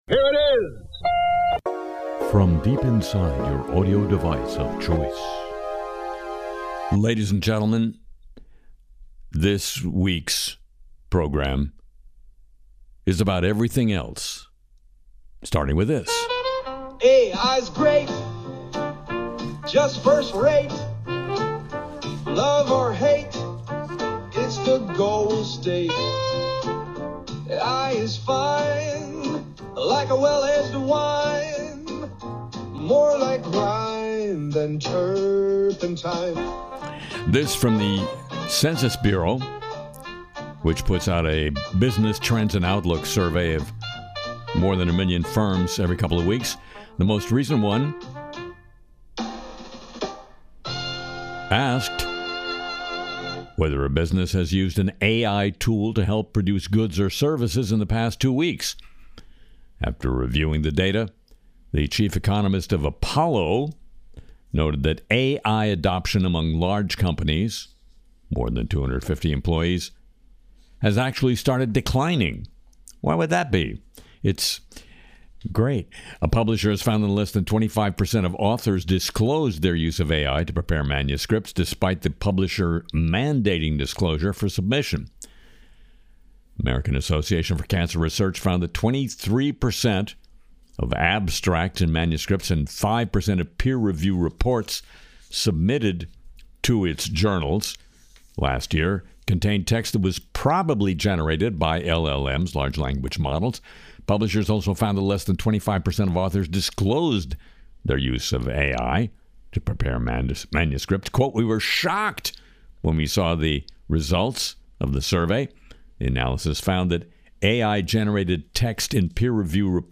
Music & Segments